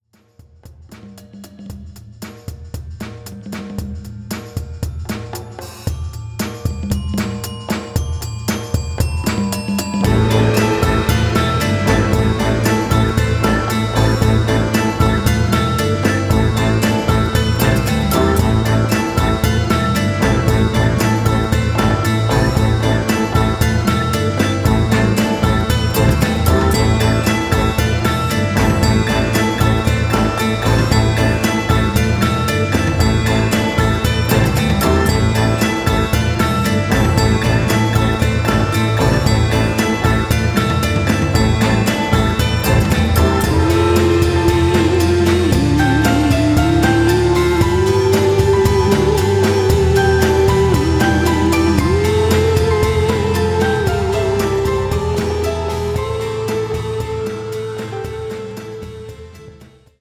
auf eine atmosphärische, psychedelische Grundstimmung